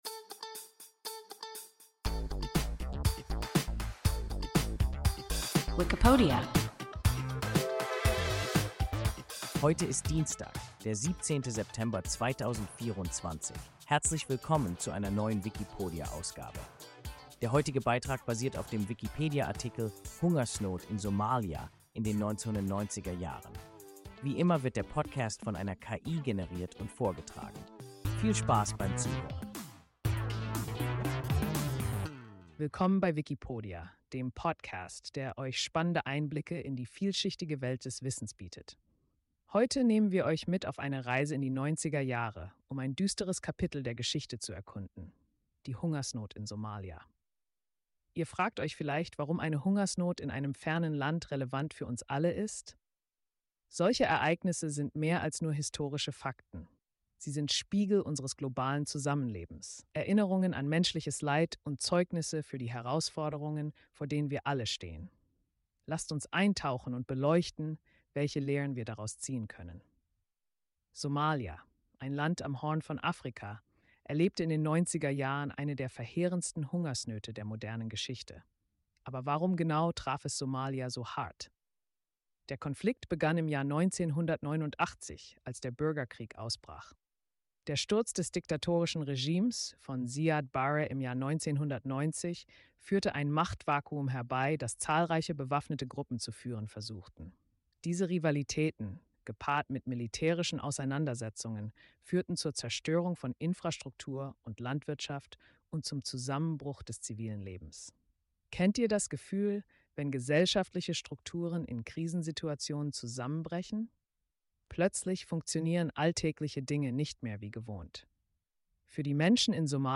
Hungersnot in Somalia in den 1990er-Jahren – WIKIPODIA – ein KI Podcast